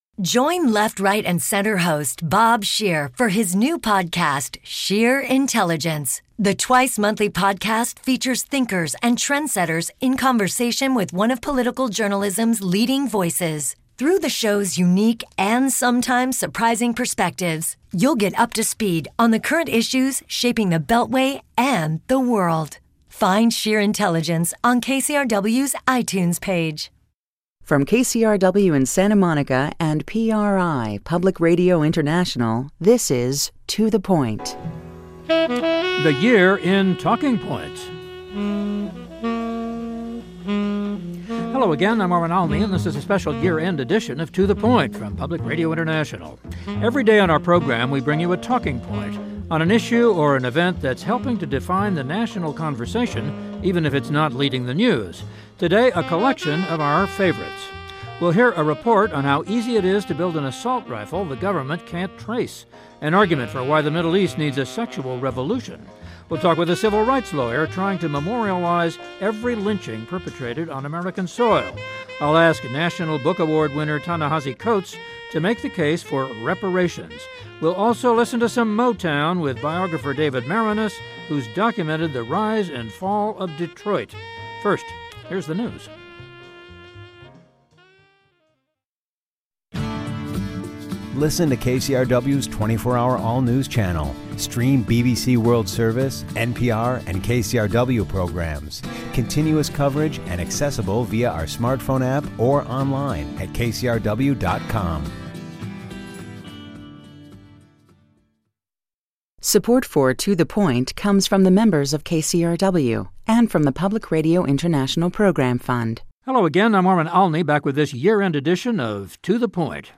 We hear a report on how easy it is to build an assault rifle the government can't trace, and an argument for why the Middle East needs a sexual revolution. We talk with a civil rights lawyer trying to memorialize every lynching perpetrated on US soil.